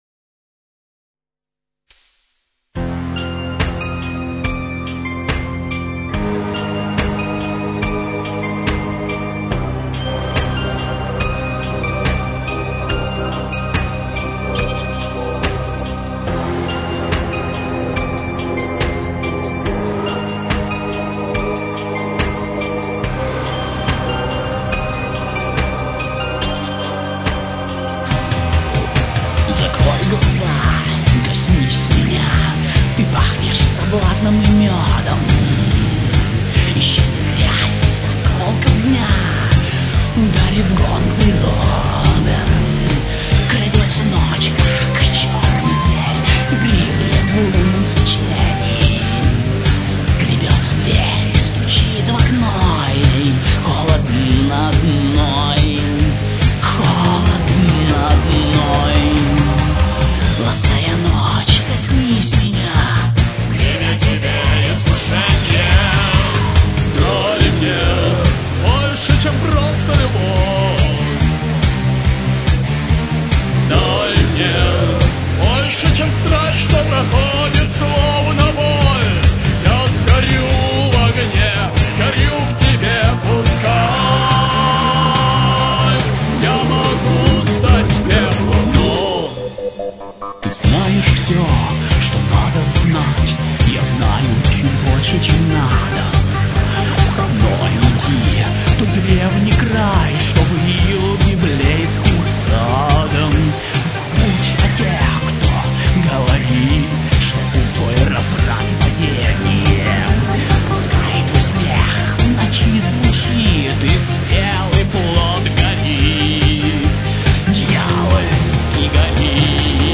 male voices, screams & other voices perversions
-         гитара
-         female vocal